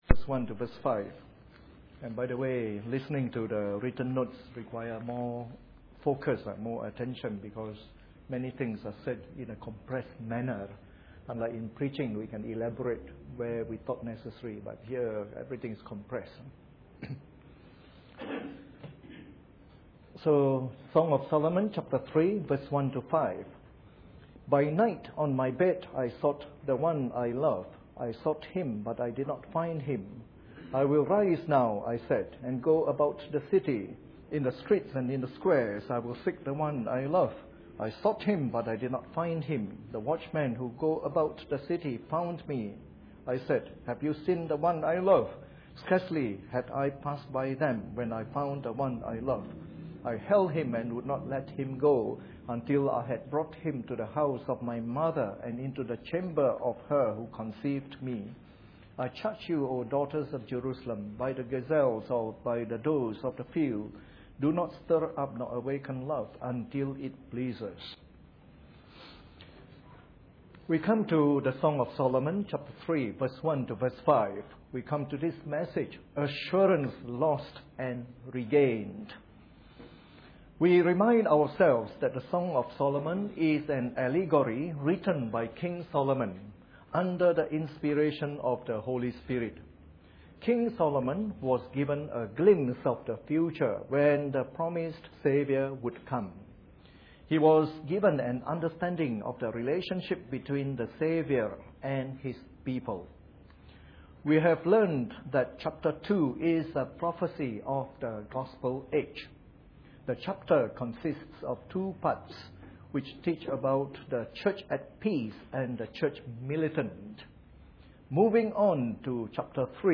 Preached on the 2nd of November 2011 during the Bible Study from our new series on the Song of Solomon.